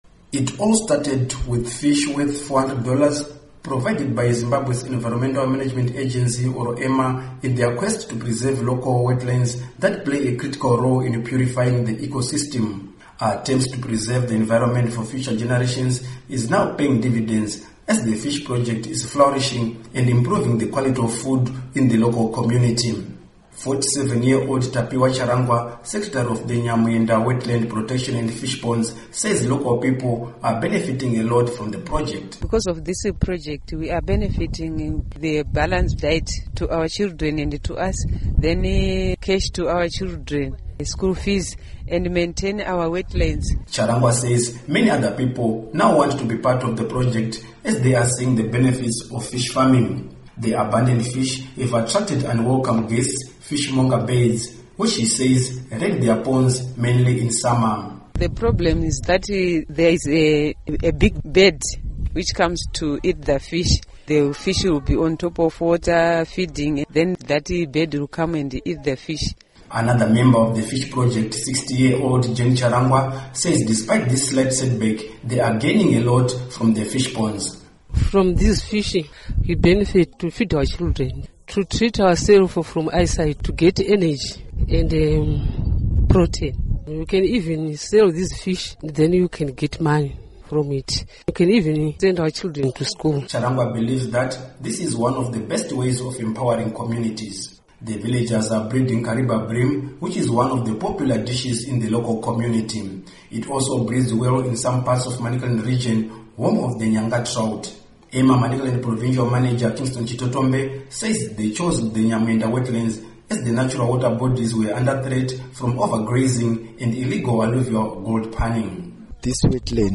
Report on Fish Farming